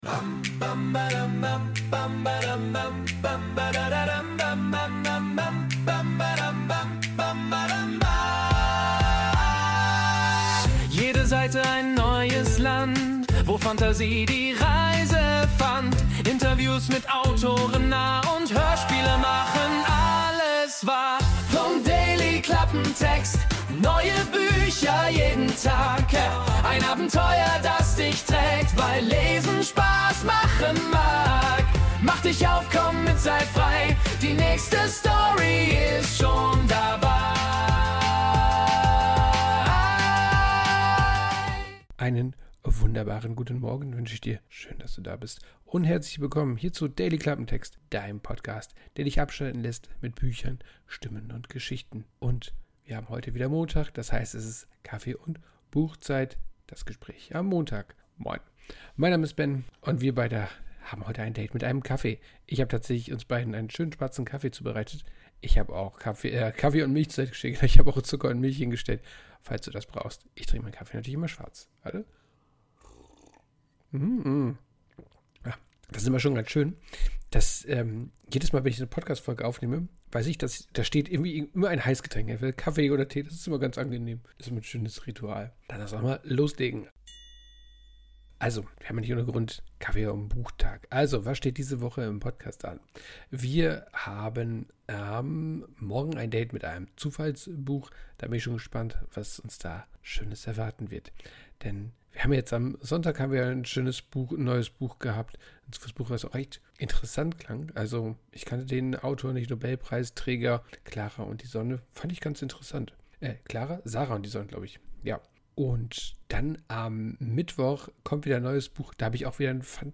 Intromusik: Wurde mit Music AI erstellt.